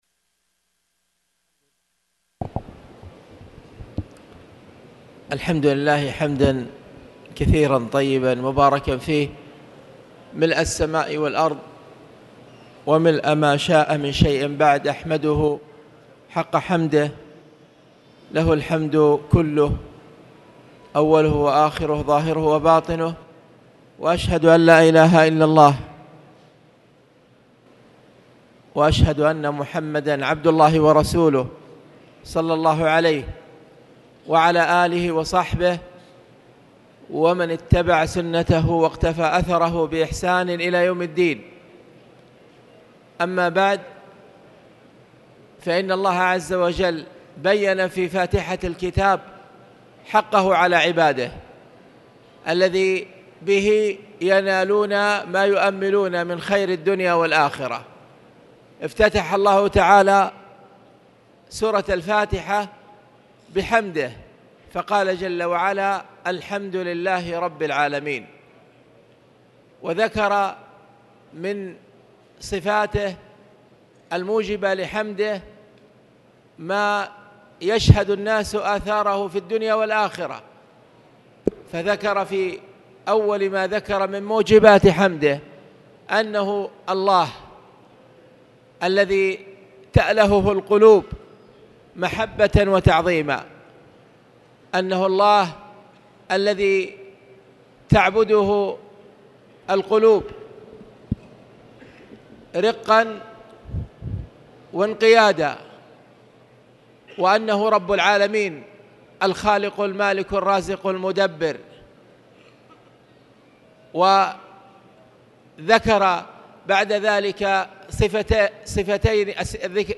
تاريخ النشر ٤ ربيع الأول ١٤٣٨ هـ المكان: المسجد الحرام الشيخ: فضيلة الشيخ أ.د. خالد بن عبدالله المصلح فضيلة الشيخ أ.د. خالد بن عبدالله المصلح باب الإستغاثة